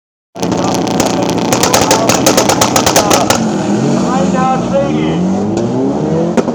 Hier noch ein kleiner Sound-Genuß:
Motoren Sound Mitsubishi Lancer